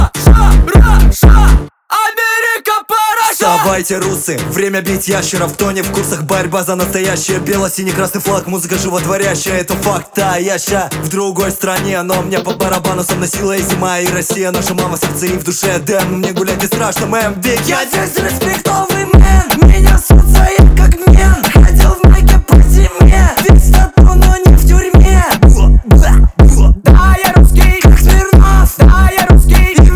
Жанр: Танцевальные / Русские
# Dance